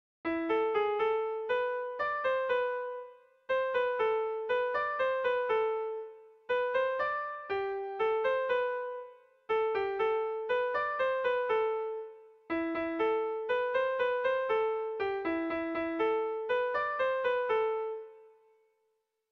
ABDAEF